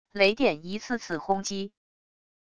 雷电一次次轰击wav音频